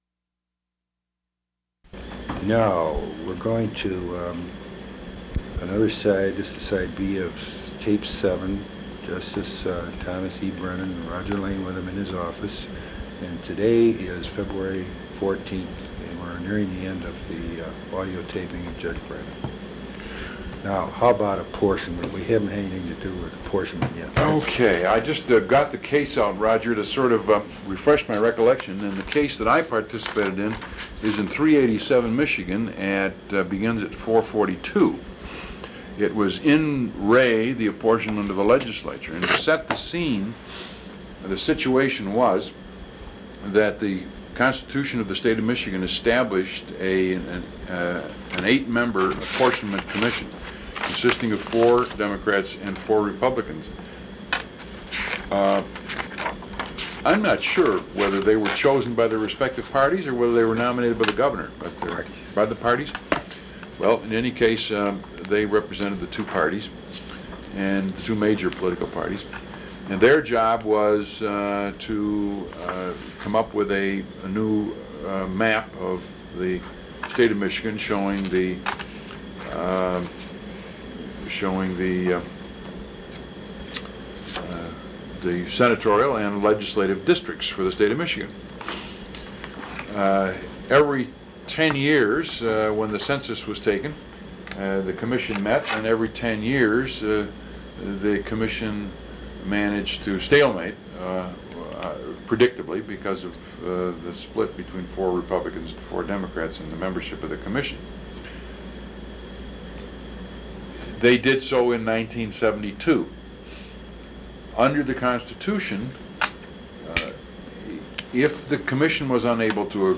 Interview with former Michigan Supreme Court Justice Thomas E. Brennan